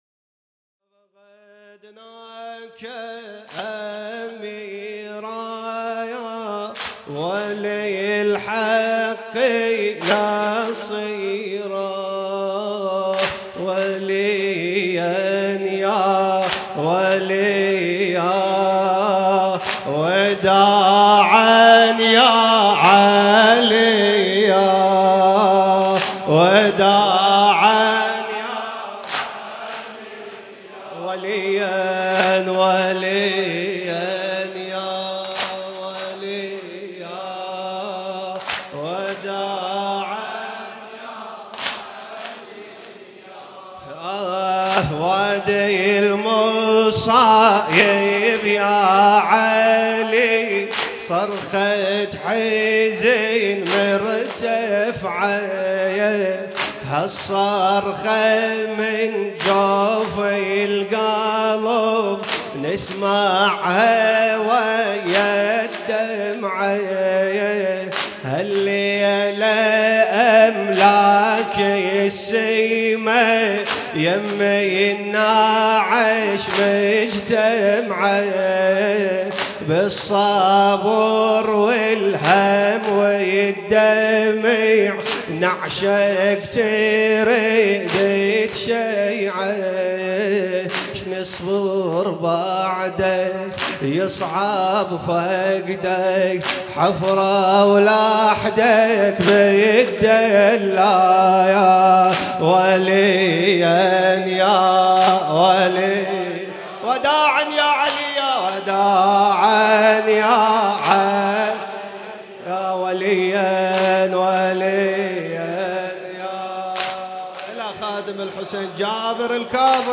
شب بیست و یکم رمضان 96 - هیئت شبان القاسم - وداعاً یا علیاً